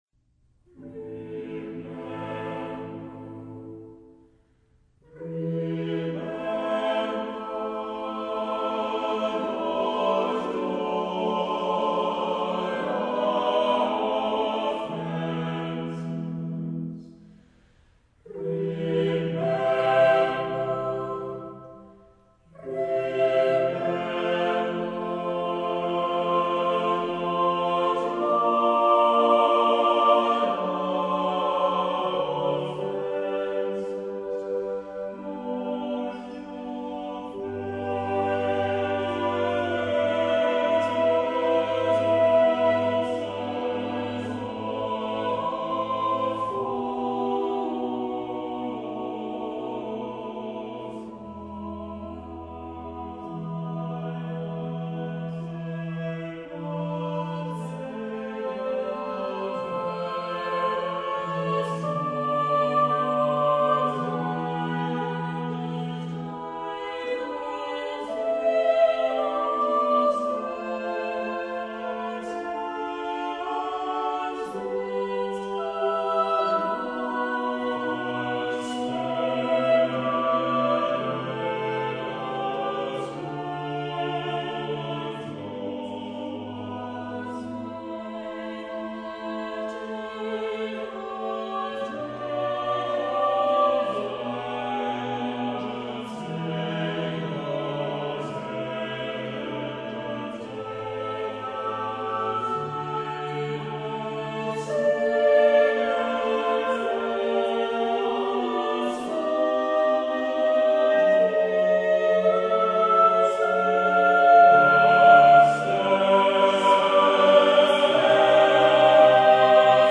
note: afin de ménager la bande passante, les fichiers en écoute immédiate sont encodés en mono, 22 KHz, 32 Kbps, ... ce qui signifie que leur qualité n'est pas "excellentissime"!
Remember not ( Oxford Camerata)
"Remember not, Lord, our offences" is a full verse anthem written for a five part ensemble. It is full of dischords and dramatic harmony. Both the beginning and the end of the work are set to a homophonic texture.
In the first half the music climaxes on this line, as the rising chromatic lines build in tension and dischord to this moment.